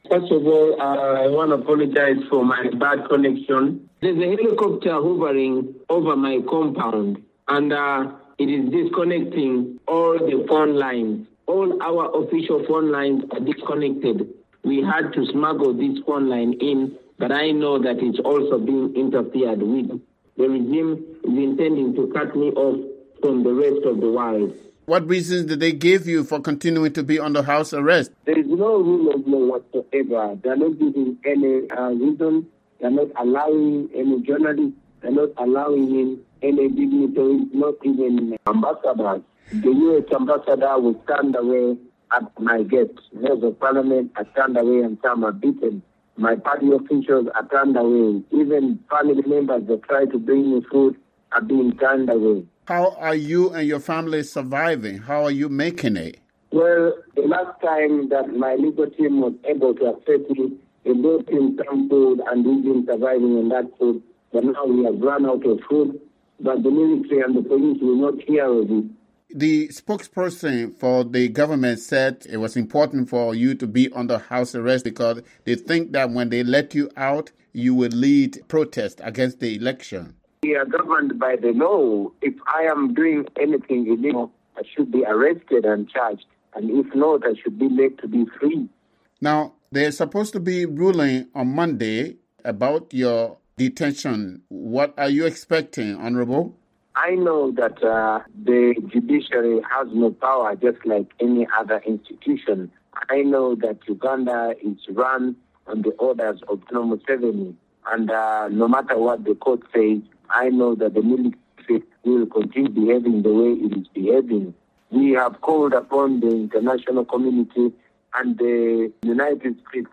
VOA Interview: Uganda's Bobi Wine Speaks Before Favorable Court Ruling